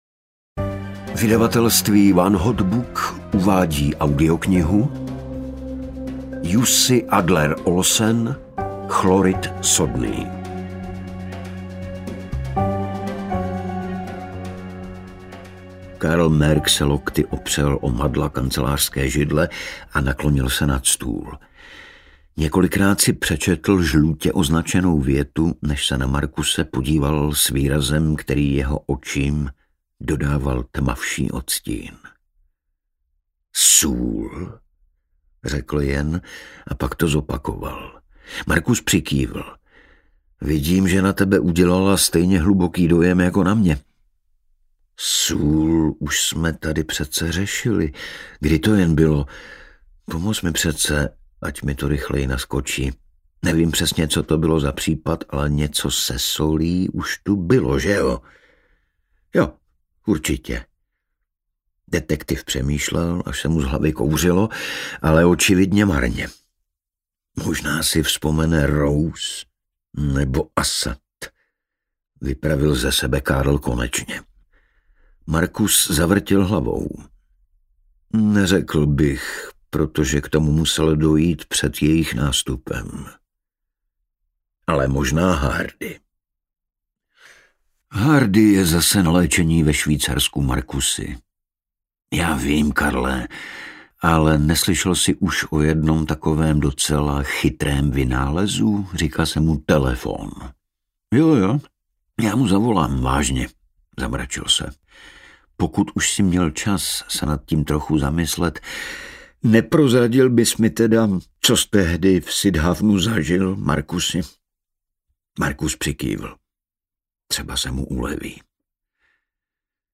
Chlorid sodný audiokniha
Ukázka z knihy
• InterpretIgor Bareš